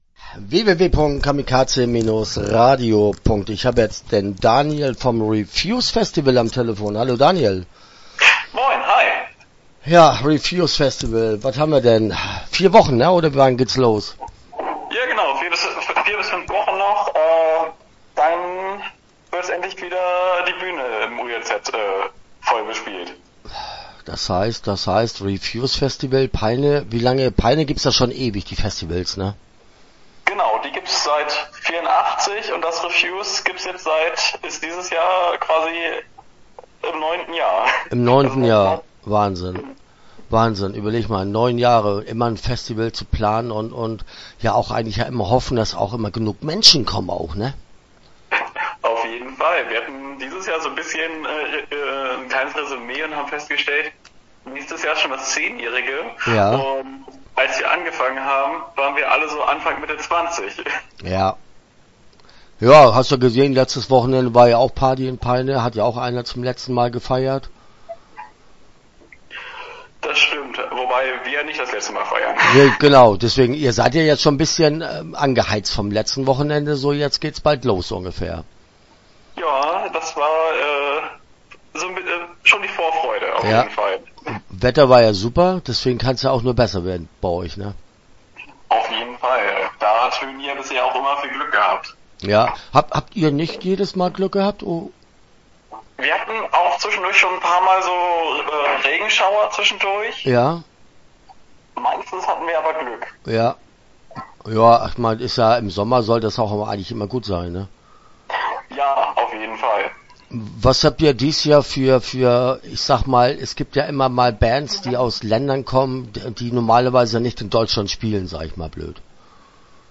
Start » Interviews » Refuse Festival - Peine